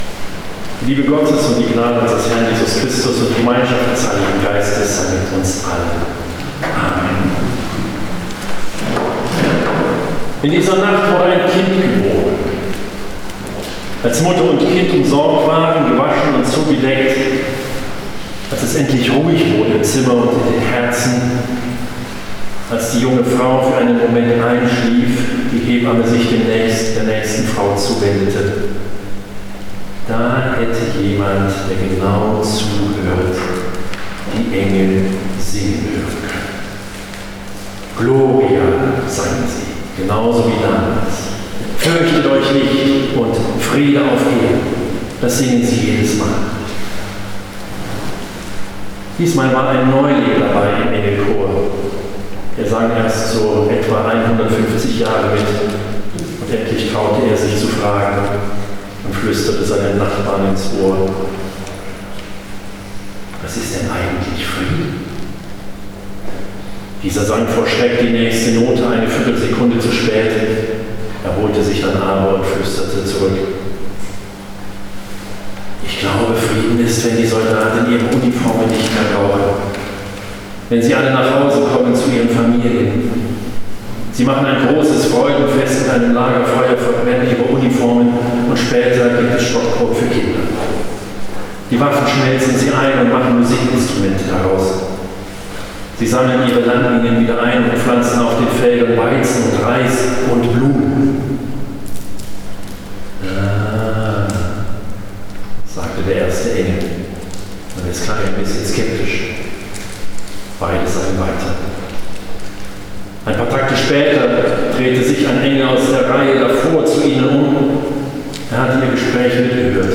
predigt
Die Predigt vom Heiligen Abend am Frauenberg zum Nachhören Downloads